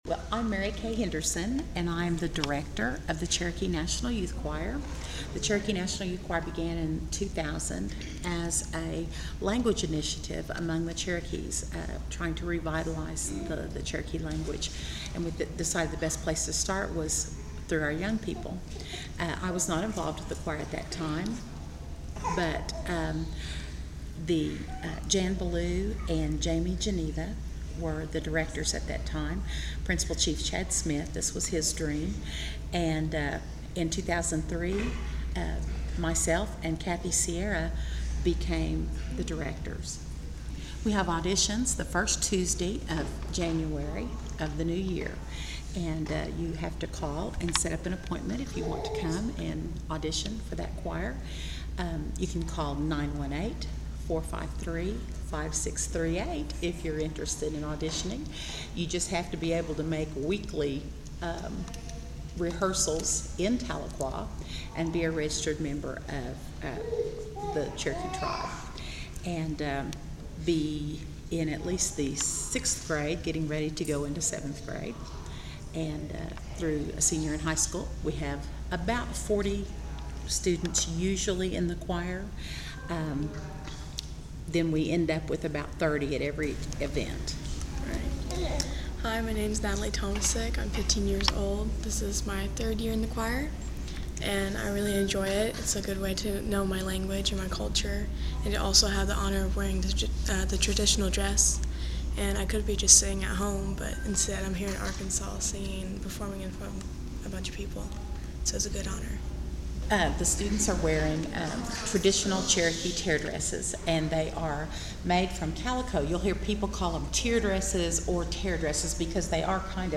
The Arkansas Chapter of the National Trail of Tears Association hosted "We Remember, " on September 11th at the Town Center in Fayetteville, to commemorate the forced removal of the Cherokees from their land base in the late 1830s.
Cherokee Choir.mp3